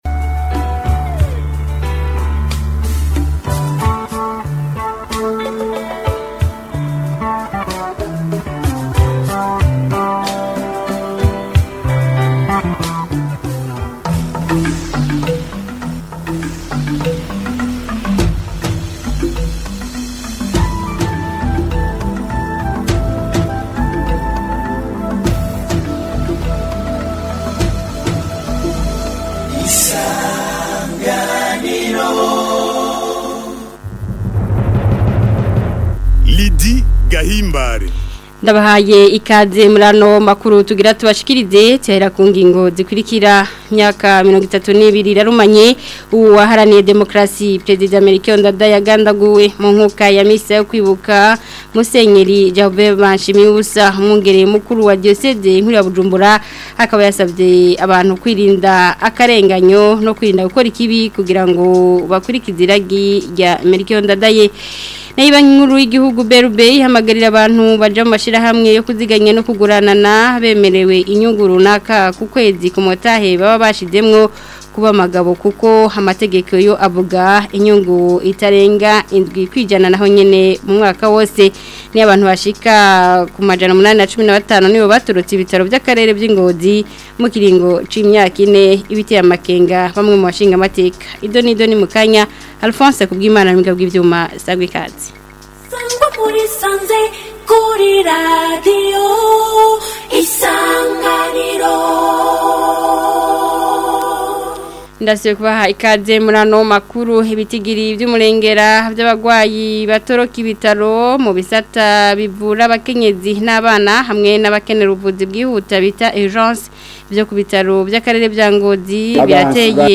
Amakuru yo ku wa 21 Gitugutu 2025